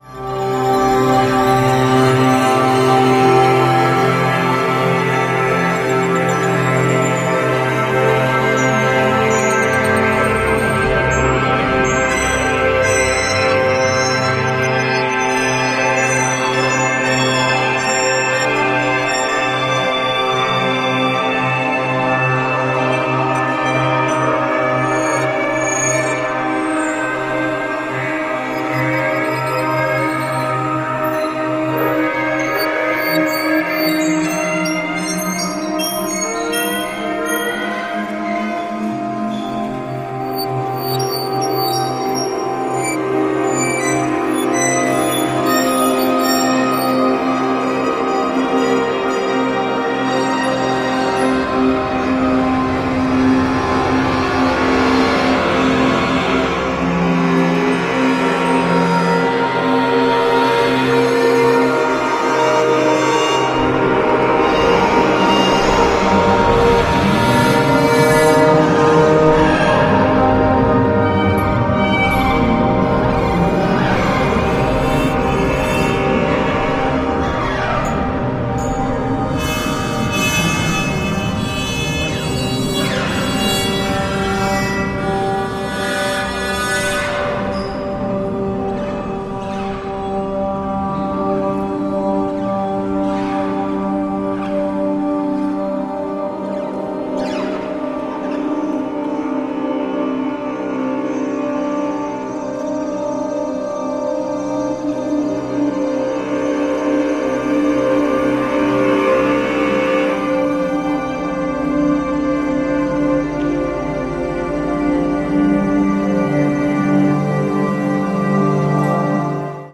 彼女はアコーディオンを操り、ディジュリドゥ、おもちゃ、トロンボーン、ピアノと共に深淵な重奏持続音を奏で続ける名演。